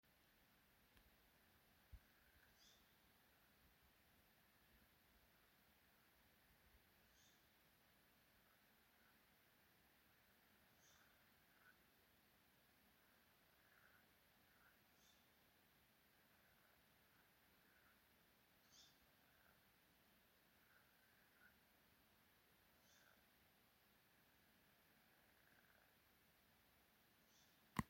Urālpūce, Strix uralensis
Piezīmes/vismaz 2 pull balsis, ad neatbild.